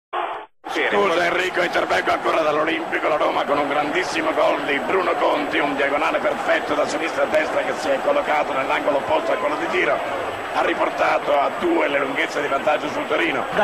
Il terzo gol di Bruno Conti (commento Sandro Ciotti)
terzo gol conti roma torino scudetto 82-83 commento sandro ciotti.wma